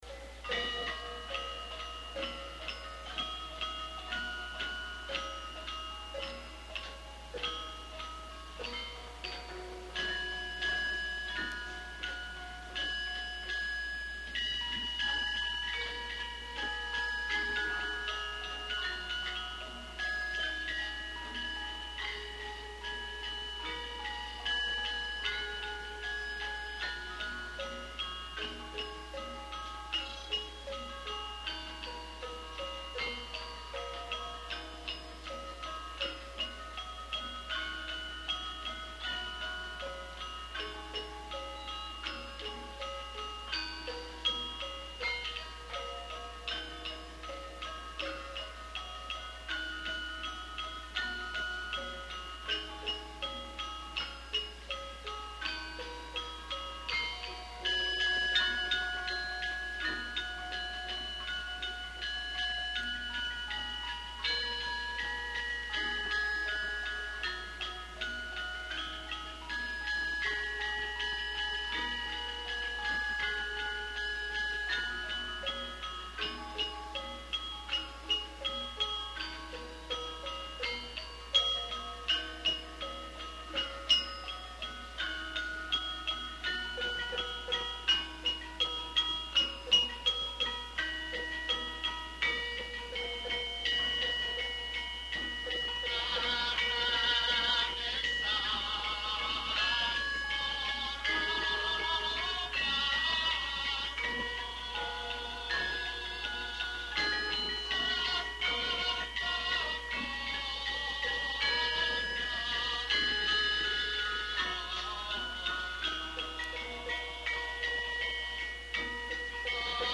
Group:  Gamelan Nyai Saraswati
Chapel Hill Museum
Ladrang Wilujeng, Laras Slendro, Pathet Manyura
01_Ladrang_Wilujeng_slendro_manyura.mp3